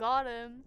Voice Lines / Barklines Combat VA
Maddie Theyre hit.wav